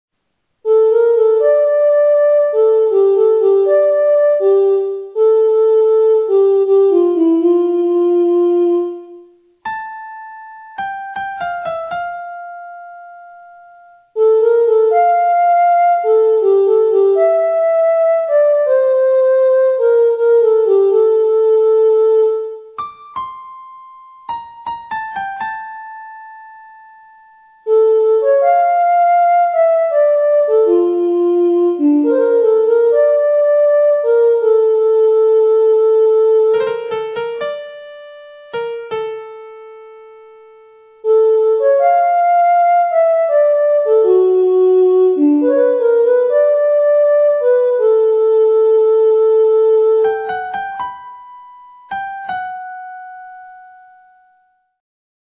After a short introduction (not shown), the first four bars contain all notes characteristic of the key of D minor.